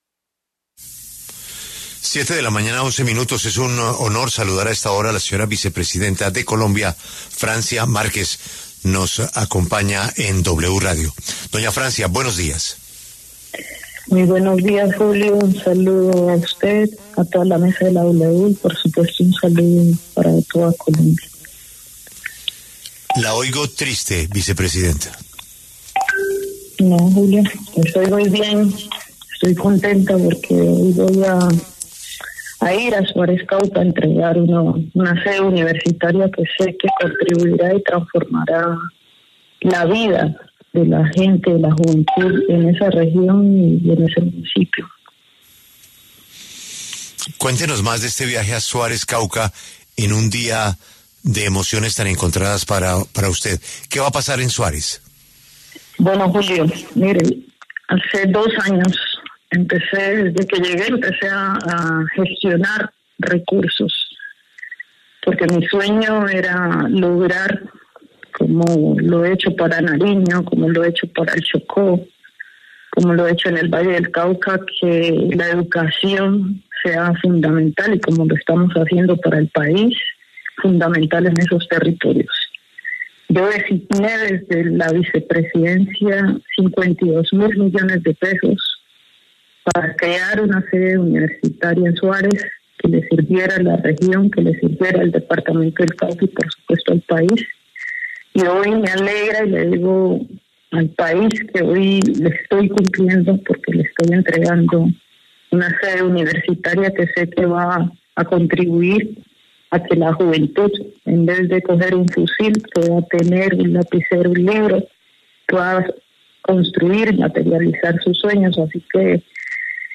Francia Márquez, vicepresidenta de Colombia, habló en La W sobre su salida del Ministerio de Igualdad, las amenazas en su contra y su papel en este último tramo del Gobierno Petro.